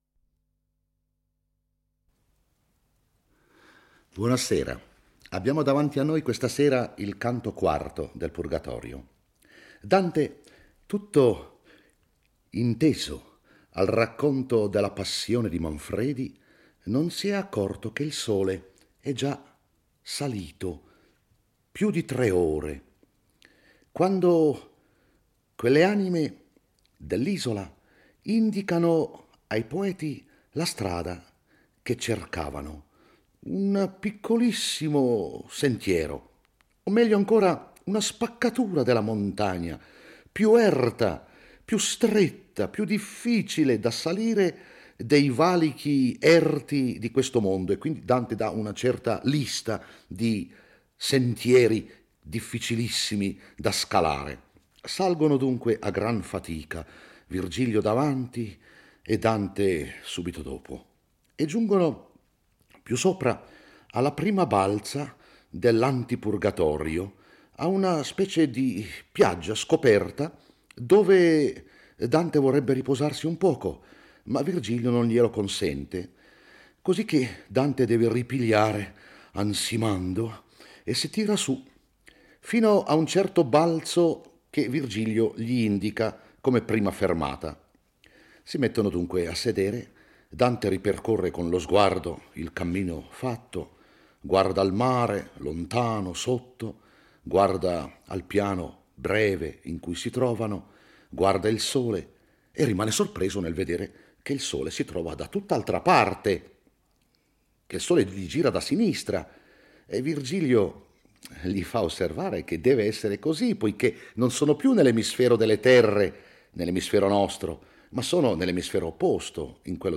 legge e commenta il IV canto del Purgatorio. Per spiegare come mai Dante non si accorge che il sole si è alzato, introduce il concetto di anima dotata di tre potenze: vegetativa, sensitiva e razionale. I due poeti giungono nel luogo in cui si apre lo stretto e ripidissimo passaggio per la salita al Purgatorio e iniziano l'ascesa.